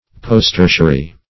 Search Result for " posttertiary" : The Collaborative International Dictionary of English v.0.48: Posttertiary \Post*ter"ti*a*ry\, a. [Pref. post- + tertiary.] (Geol.) Following, or more recent than, the Tertiary; Quaternary.